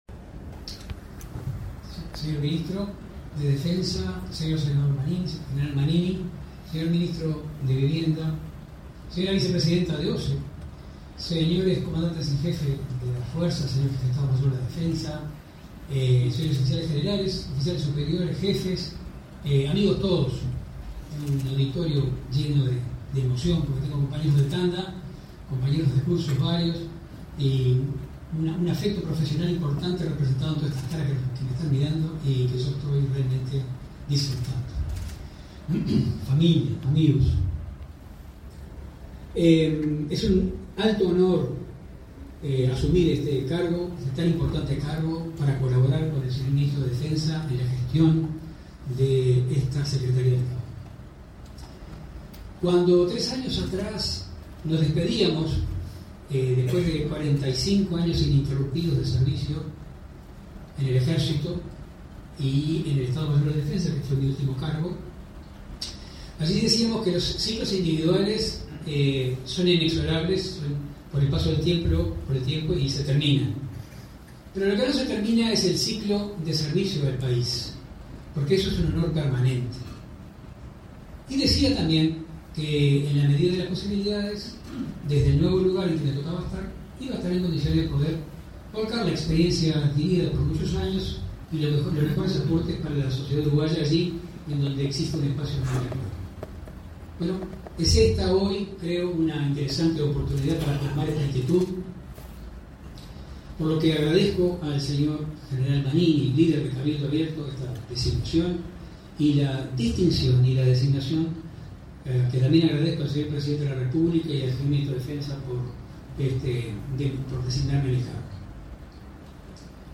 Palabras del subsecretario de Defensa Nacional, Marcelo Montaner